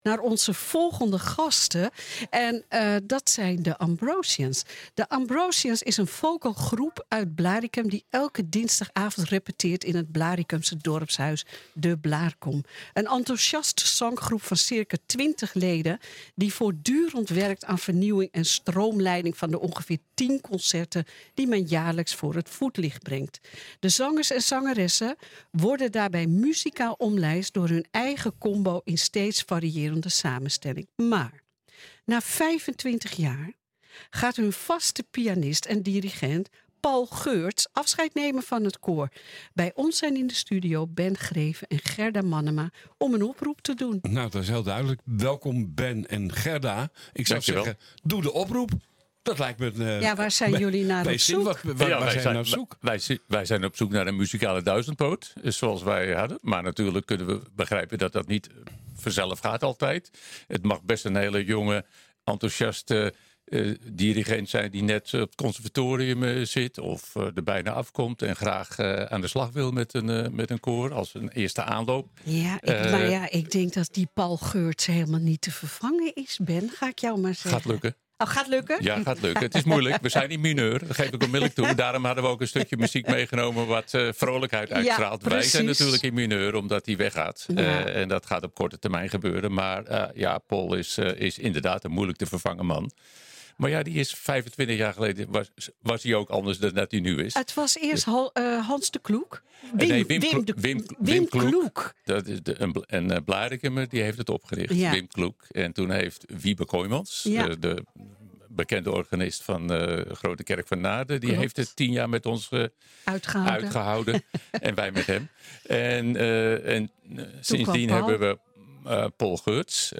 Bij ons zijn in de studio